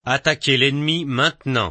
AoE2 Taunt FR 31 - attack an enemy now